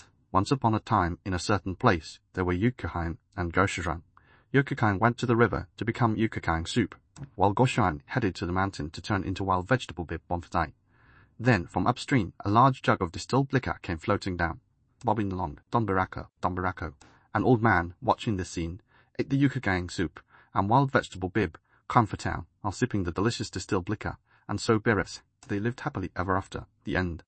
Grokで英語に翻訳したのち、英文を読ませてみました。